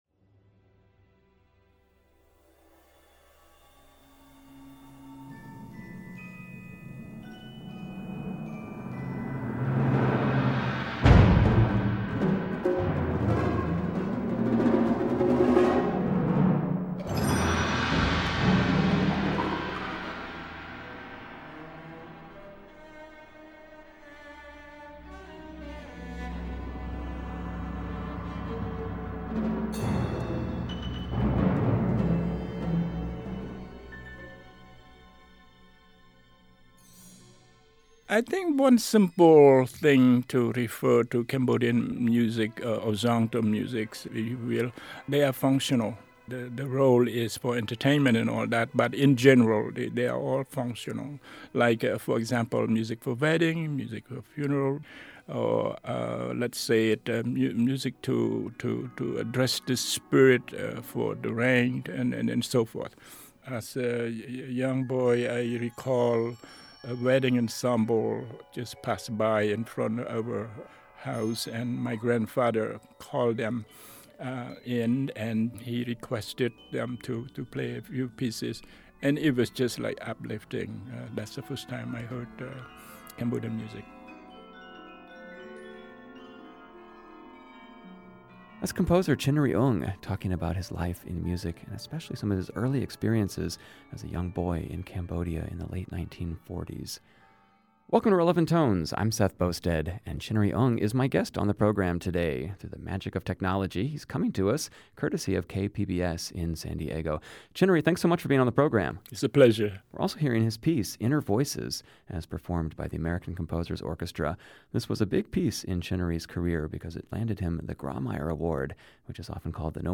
Over the years he has helped many family members and friends escape that regime and has documented his struggles in music. He talks to Relevant Tones about his life and music.